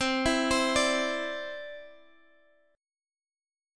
Listen to Cadd9 strummed